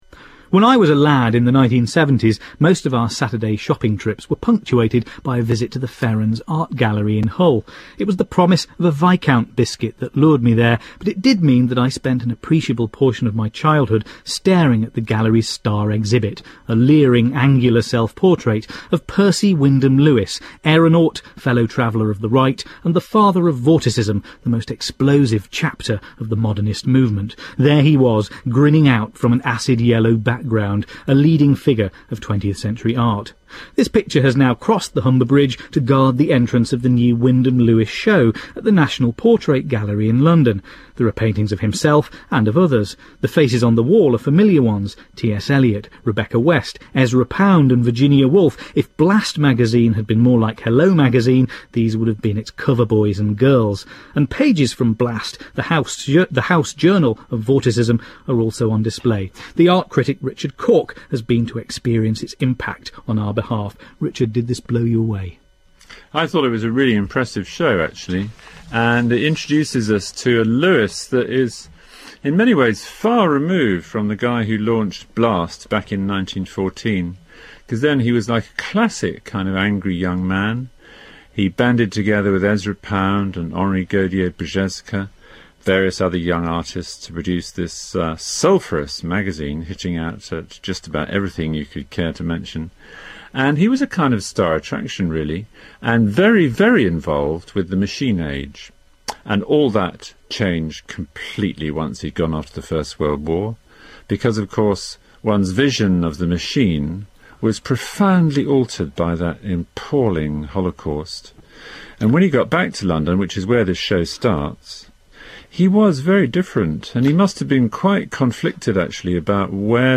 Listen to Lewis expert Richard Cork discuss the exhibition with Matthew Sweet: